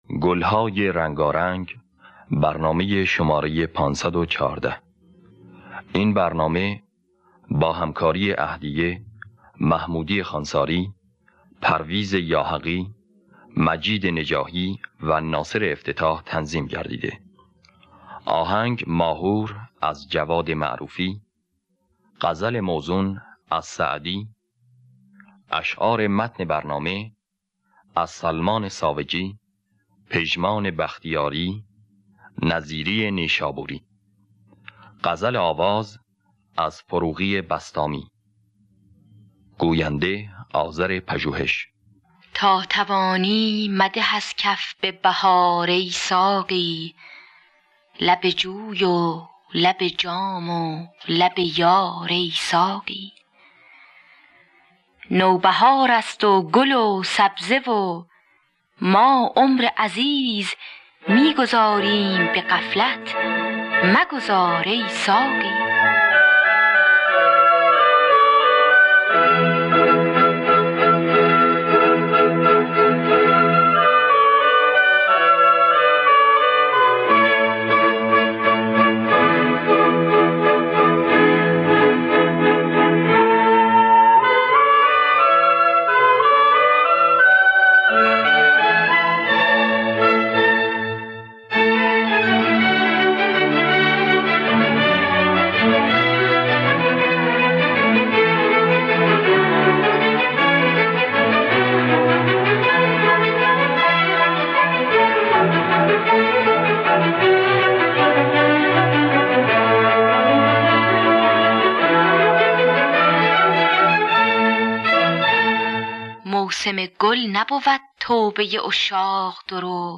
در دستگاه ماهور